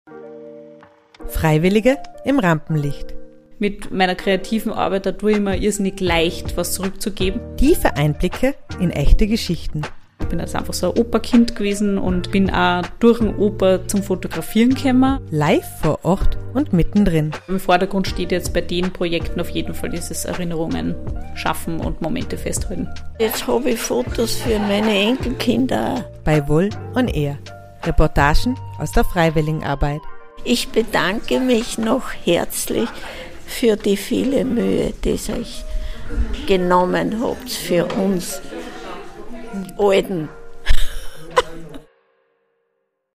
direkt vor Ort, mit viel Interaktion und spannenden Gesprächen.